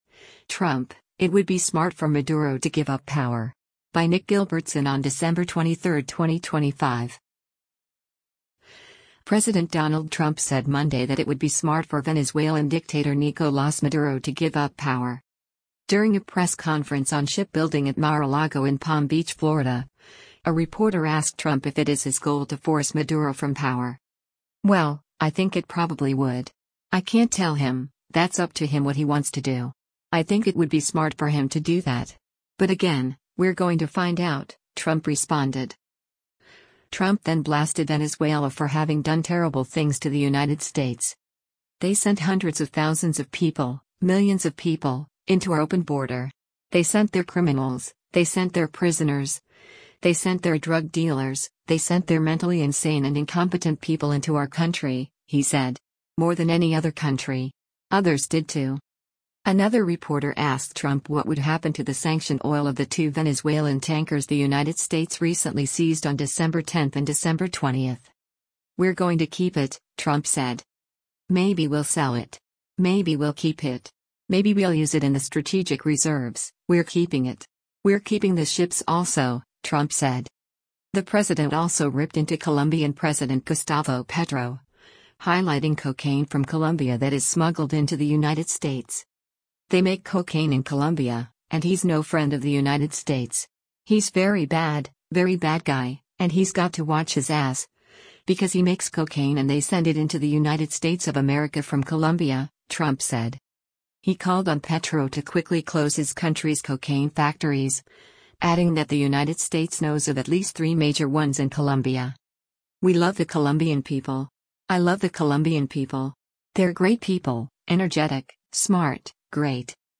During a press conference on shipbuilding at Mar-a-Lago in Palm Beach, Florida, a reporter asked Trump if it is his goal to force Maduro from power.